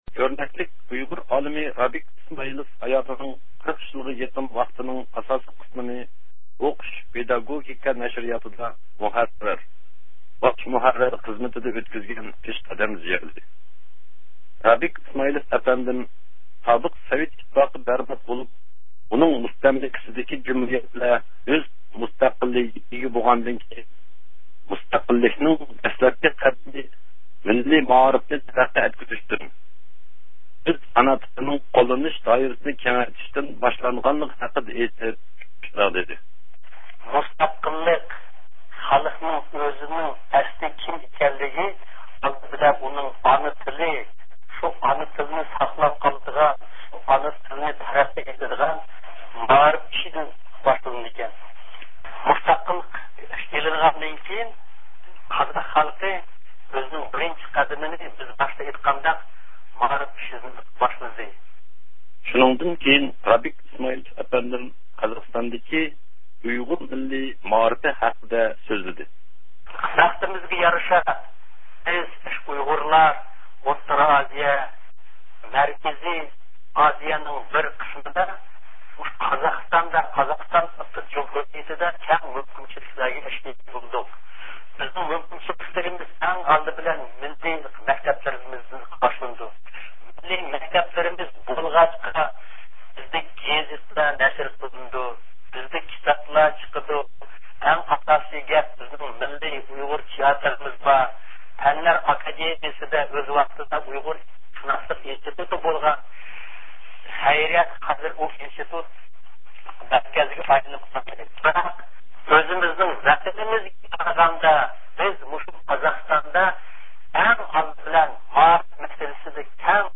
سۆھبەت ئۆتكۈزدى.